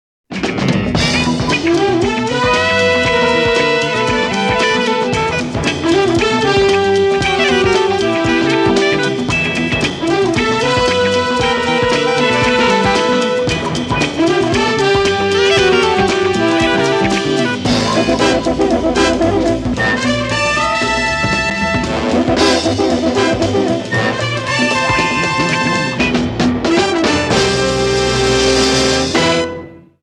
SEASON 4 THEME MUSIC:
End Credits